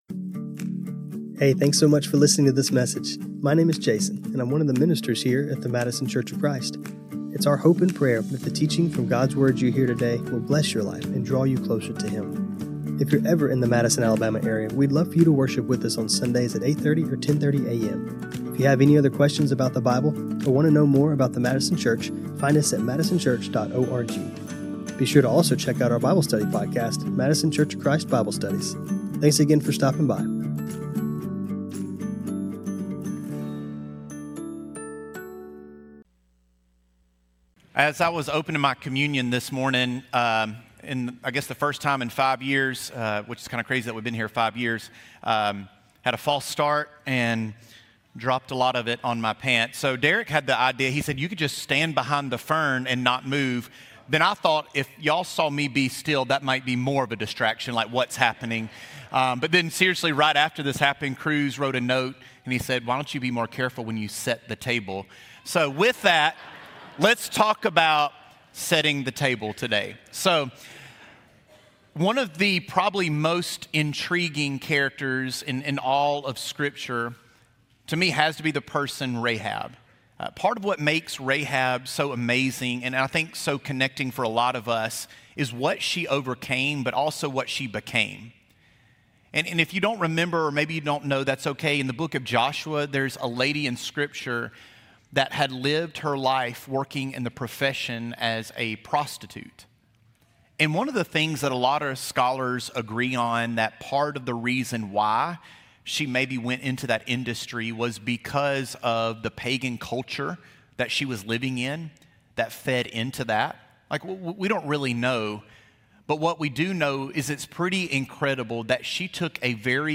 This sermon was recorded on Mar 15, 2026.